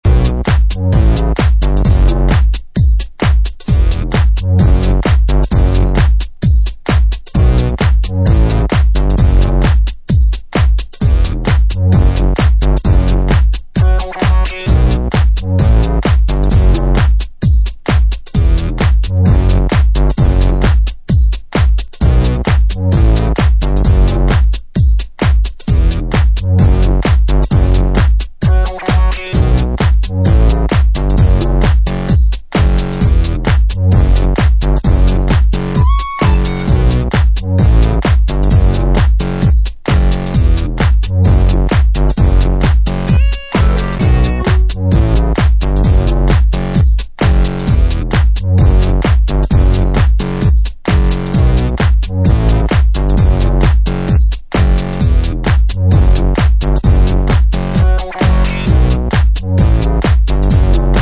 New tech funk Detroit house mix
House Techno Detroit